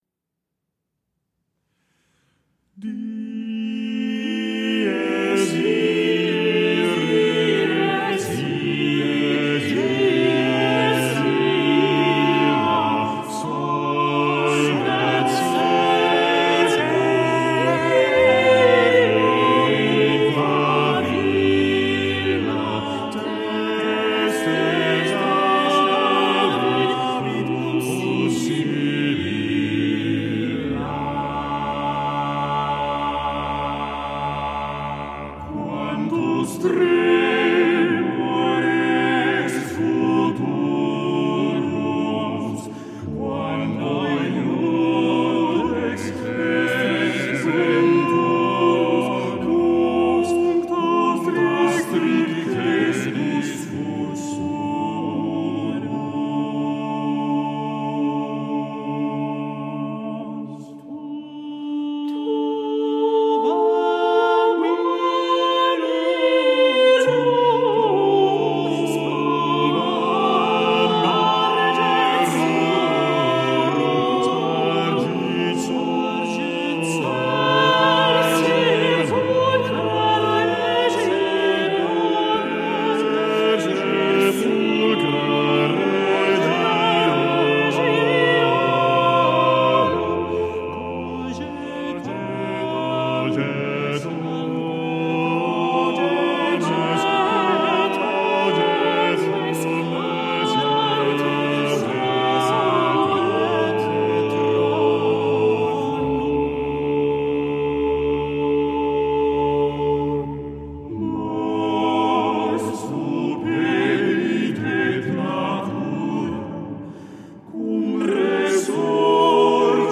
eight-voice